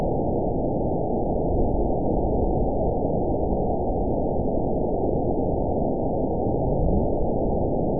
event 920361 date 03/18/24 time 03:59:32 GMT (1 year, 1 month ago) score 8.24 location TSS-AB07 detected by nrw target species NRW annotations +NRW Spectrogram: Frequency (kHz) vs. Time (s) audio not available .wav